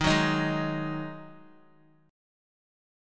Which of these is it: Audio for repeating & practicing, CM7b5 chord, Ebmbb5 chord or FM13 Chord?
Ebmbb5 chord